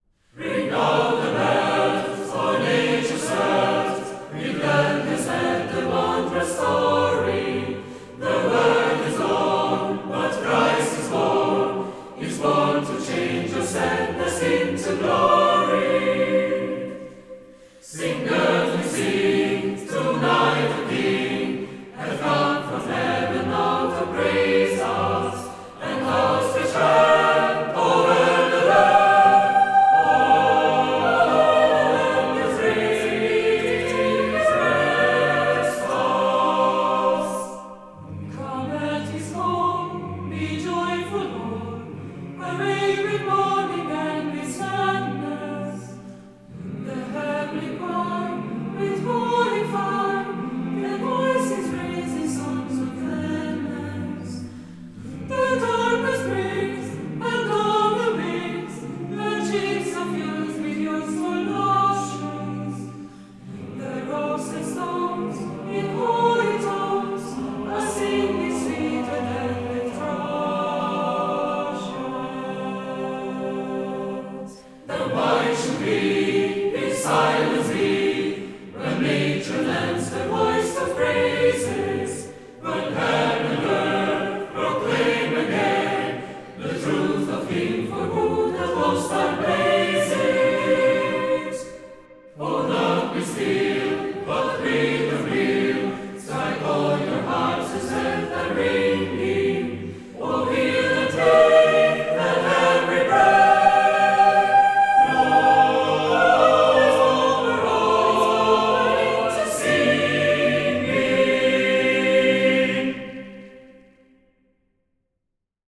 Instrumente - Mixed Choir Tempo - Medium BPM - 92